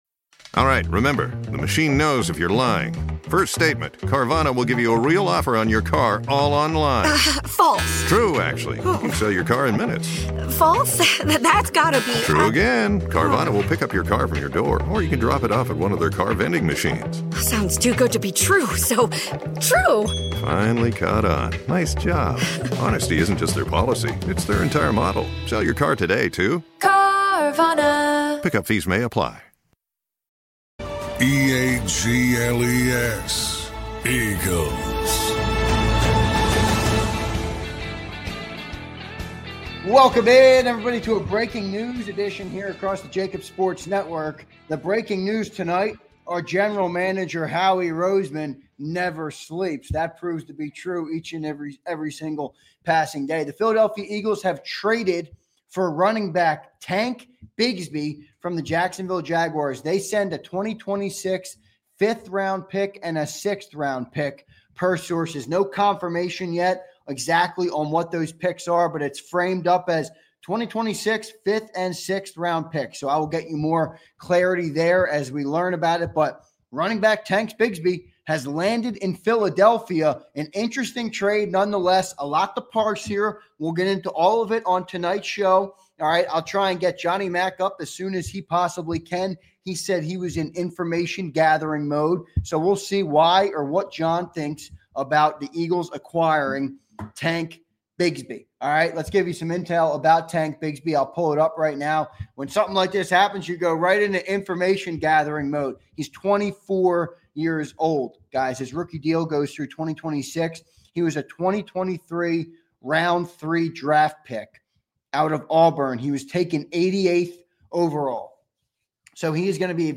The guys react & break down all the Eagles training camp stories!